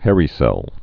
(hârē-sĕl)